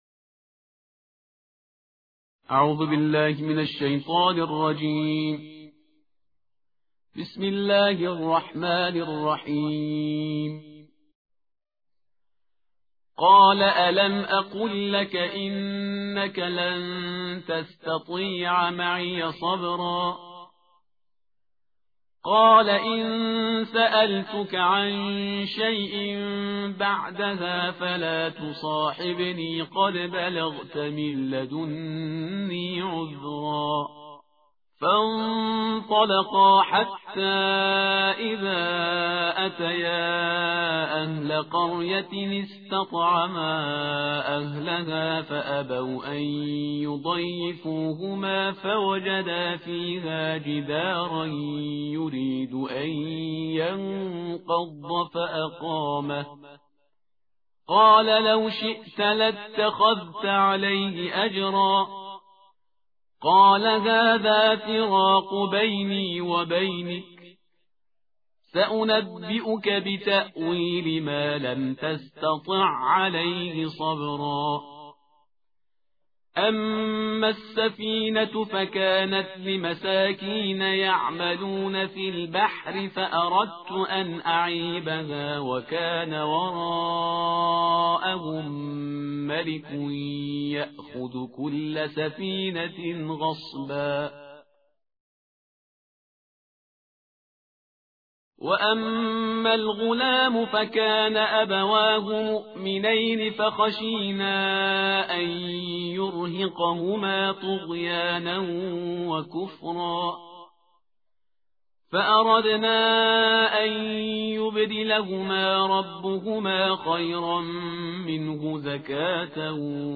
قرائت جزء و دعای روز شانزدهم و مطالب خواندنی - تسنیم